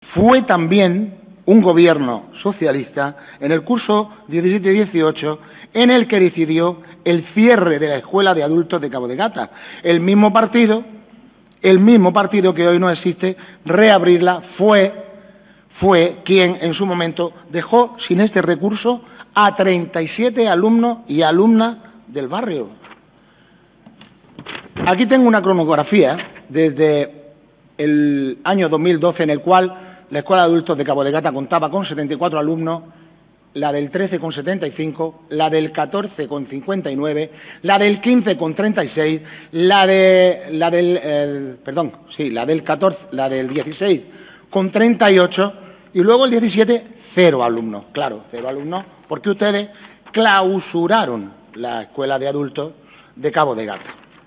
El Equipo de Gobierno del Ayutamento de Almería ha reiterado hoy su compromiso con la educación de personas adultas durante el debate de la moción, elevada hoy al Pleno de la Corporación del Ayuntamiento de Almería, en relación a la reapertura de la escuela de adultos de Cabo de Gata, moción rechazada por mayoría.
El concejal de Cultura, Diego Cruz, subrayaba en su intervención en este punto que “la educación para adultos es una herramienta esencial para garantizar la igualdad de oportunidades y el ejercicio pleno de los derechos ciudadanos”.
DIEGO-CRUZ-CIERRE-PSOE-CENTRO-ADULTOS-CABO-GATA.wav